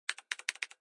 点击电脑鼠标按键
描述：干净的鼠标点击记录版本。 没有噪音，没有背景，没有空间。
标签： 点击 电脑 按键 鼠标
声道单声道